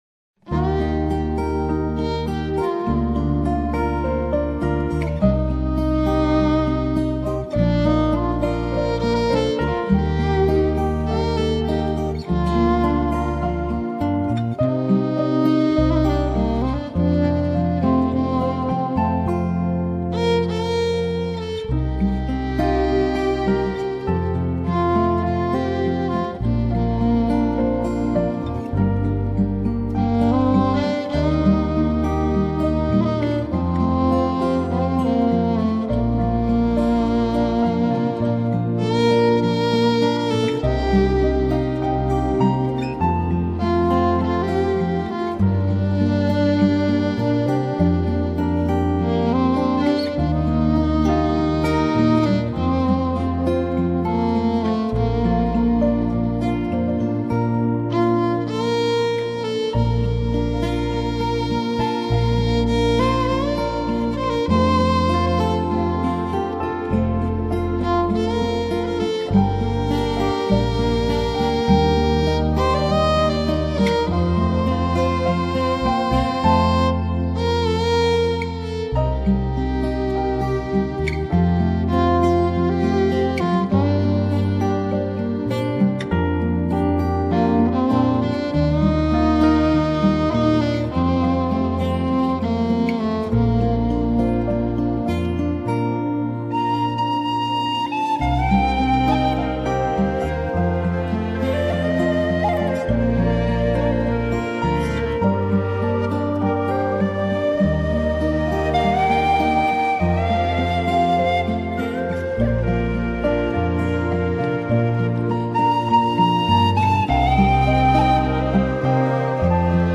这是一张充满传统歌曲隽永旋律的专辑